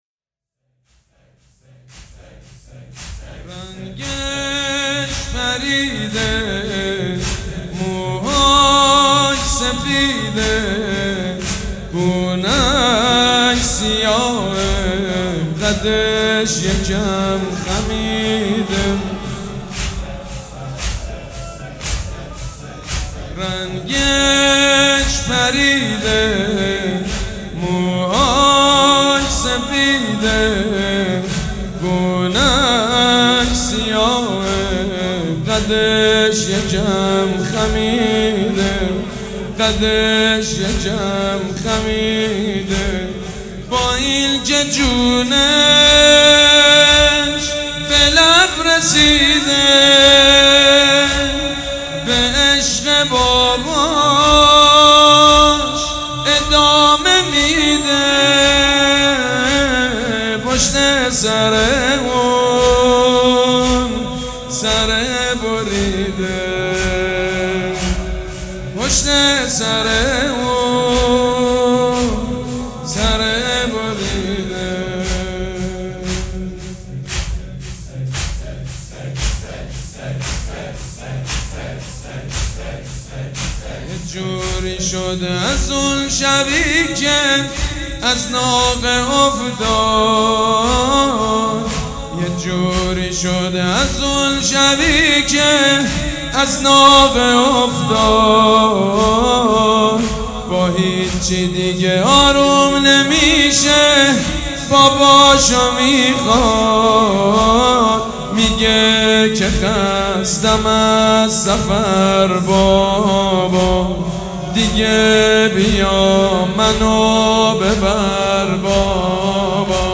سبک اثــر زمینه
مداح حاج سید مجید بنی فاطمه
مراسم عزاداری شب سوم